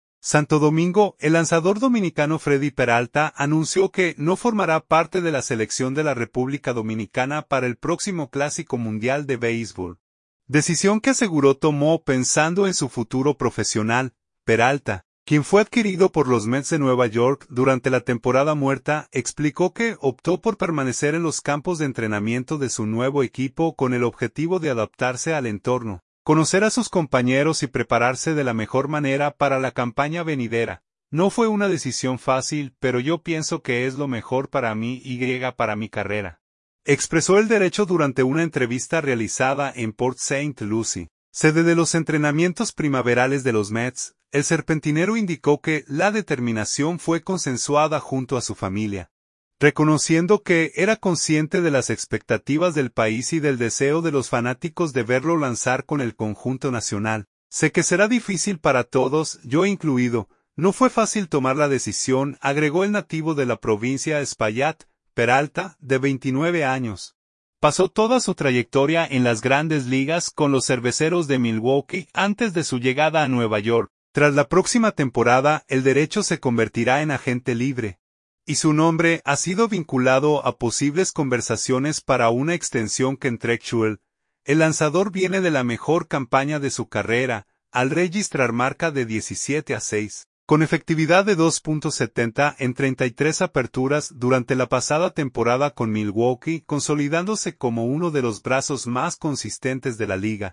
“No fue una decisión fácil, pero yo pienso que es lo mejor para mí y para mi carrera”, expresó el derecho durante una entrevista realizada en Port St. Lucie, sede de los entrenamientos primaverales de los Mets.